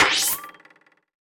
playerHighJump.wav